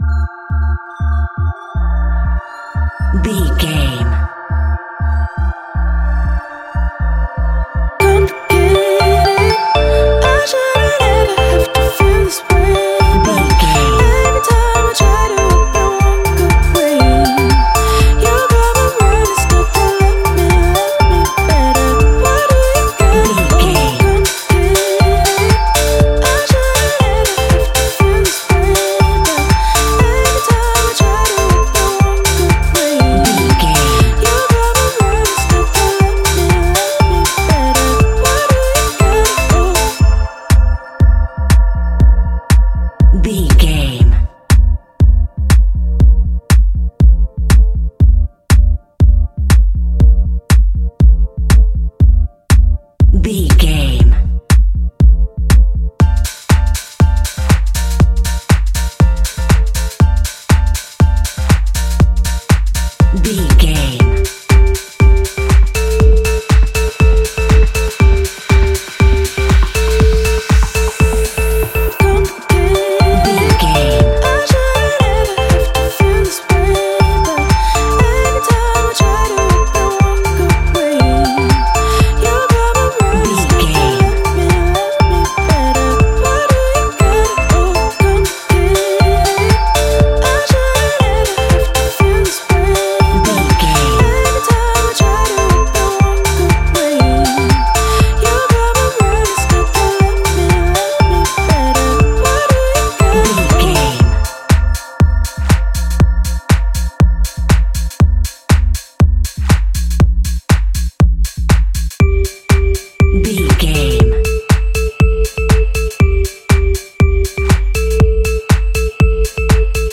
Ionian/Major
house
electro dance
synths
techno
trance
instrumentals